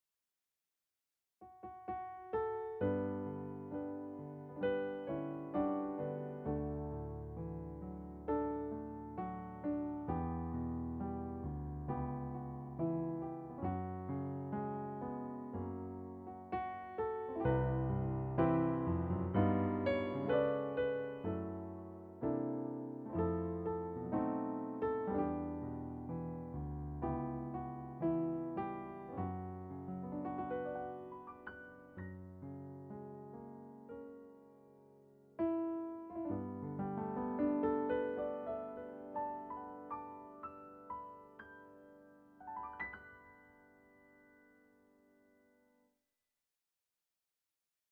הירמון ג’אזי
פסנתרן